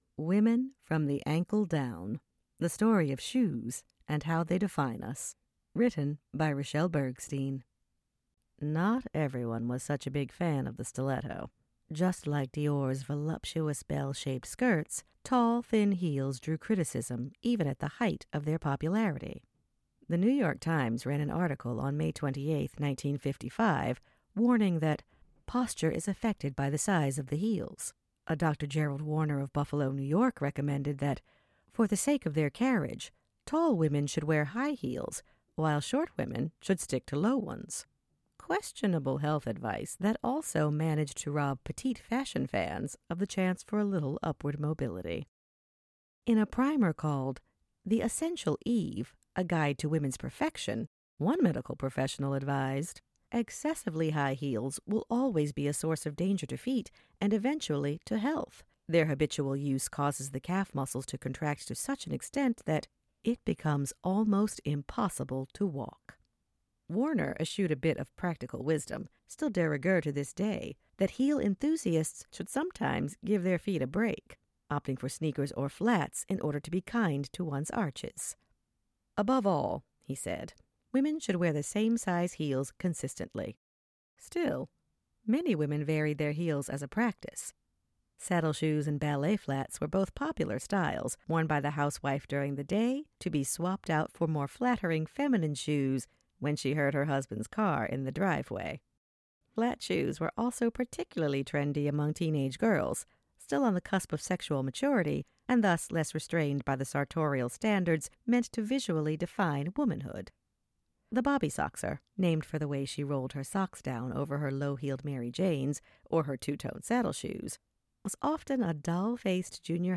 Audiobook Demo